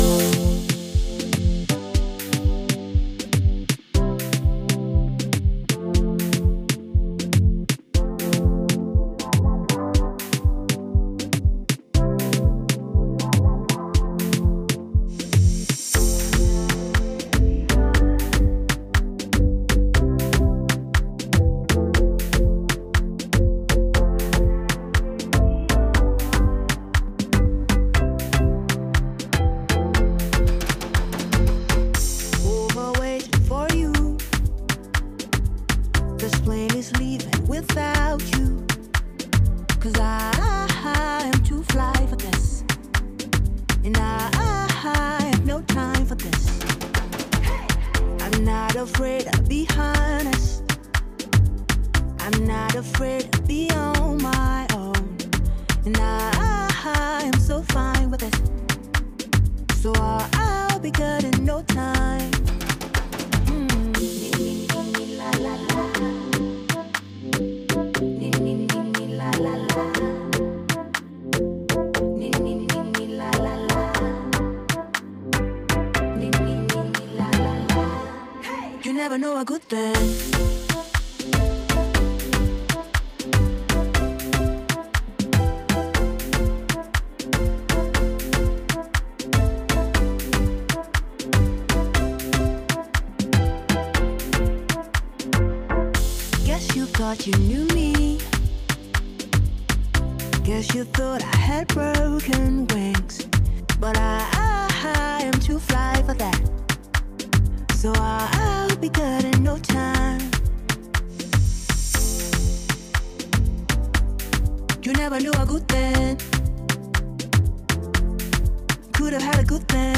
making it a proper Afro-dance tune.